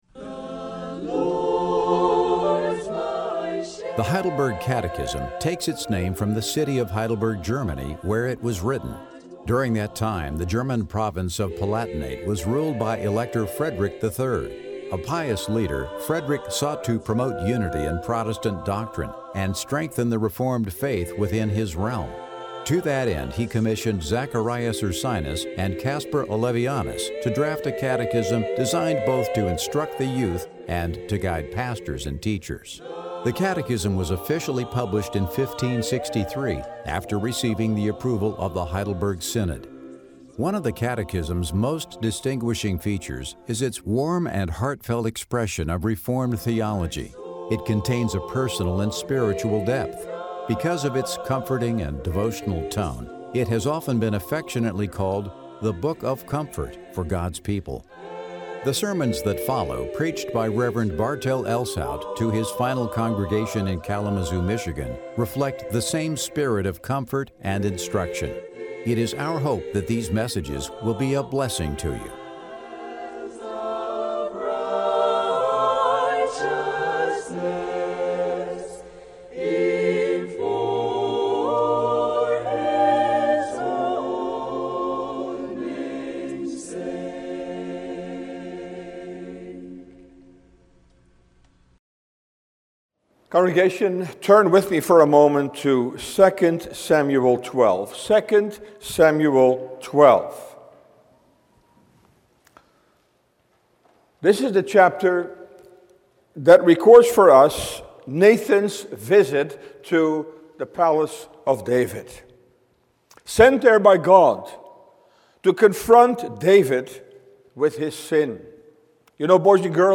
Sermon Downloads